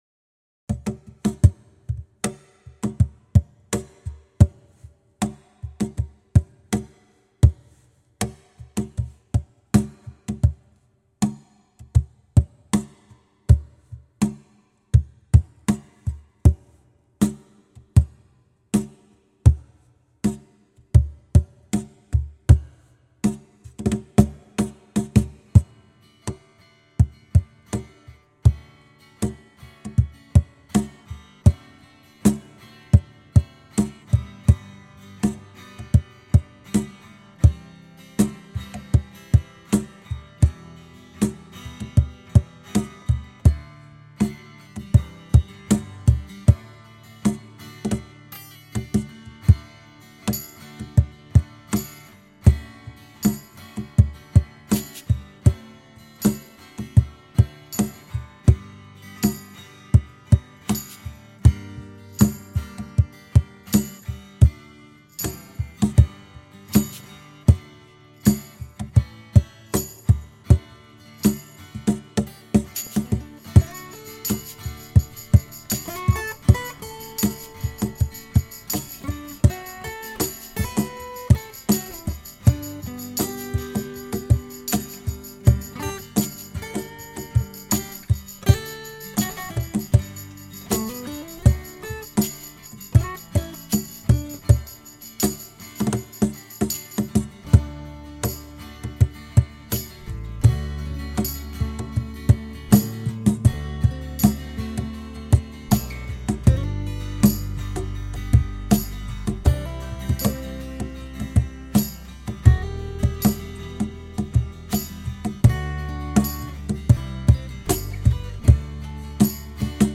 GENRE: Pop
En lugn låt som jag ursprungligen skrev sommaren 1985.
I den versionen finns ingen sång men en tydligare presentation av ljudet från min hembyggda Cajon.
Jag har spelat in min cajon i ett stereospår med en B1:a framför och en dynamiskt mikrofon bakom ljudhålet.
Virvelrasslet är inte högt, rent av icke närvarande.
Gillar den akustiska sättning